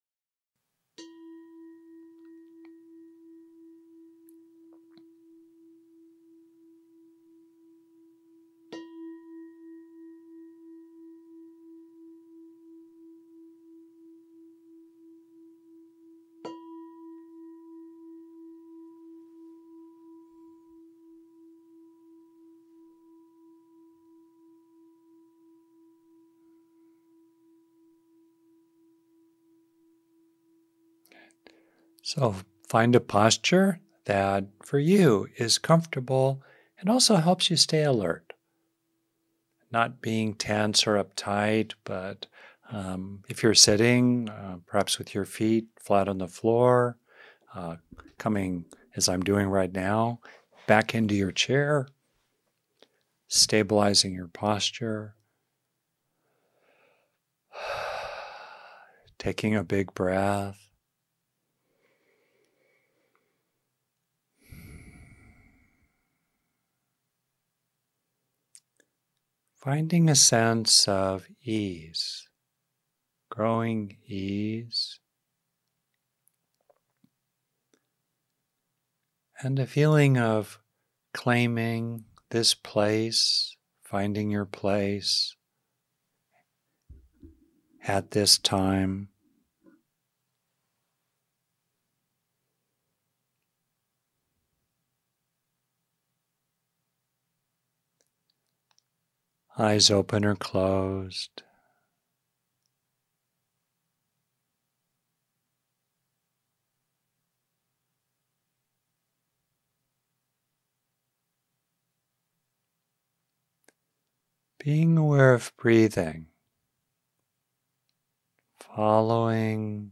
A substantial archive of talks given at the San Rafael Meditation group led by Rick Hanson, with occasional guest teachers.
These talks (mainly by Rick Hanson from his sitting group) explore practical wisdom from one of the world’s oldest contemplative traditions.
7_14_21FeelingCalmandStronginYourRelationshipsMed.mp3